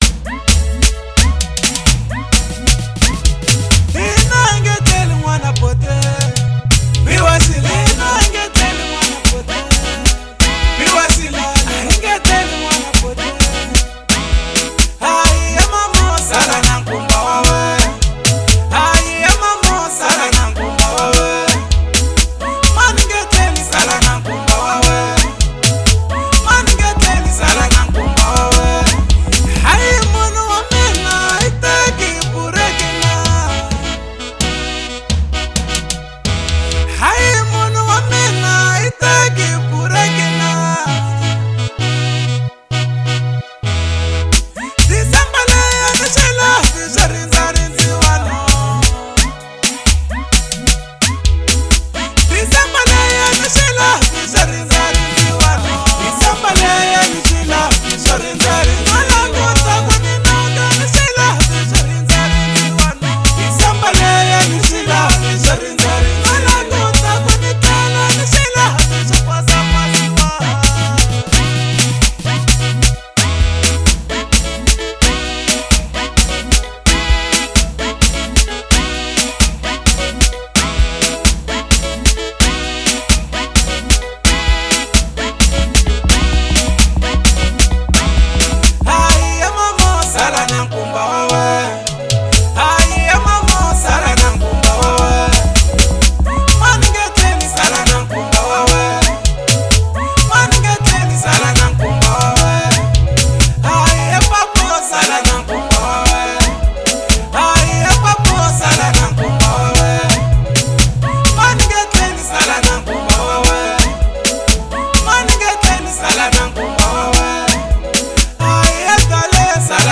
Local House